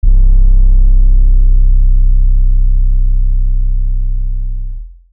Bass - Rio.wav